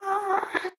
1.21.4 / assets / minecraft / sounds / mob / ghast / moan2.ogg
moan2.ogg